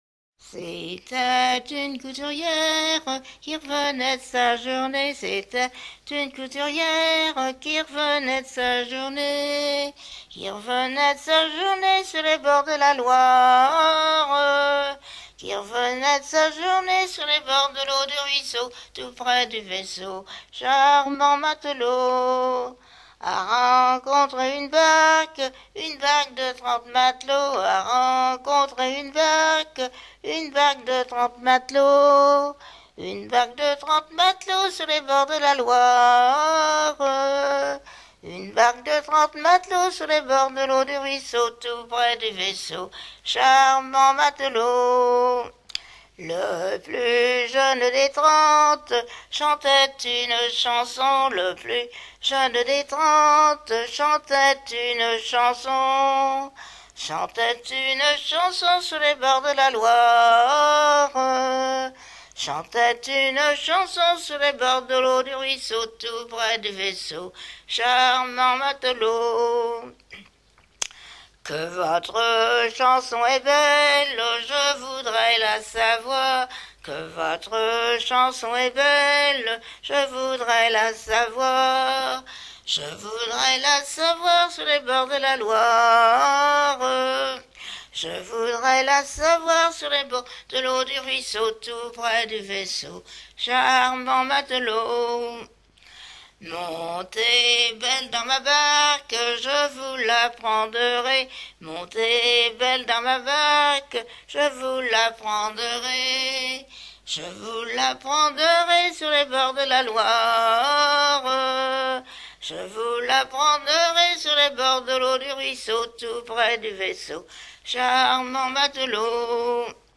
gestuel : à marcher
Genre laisse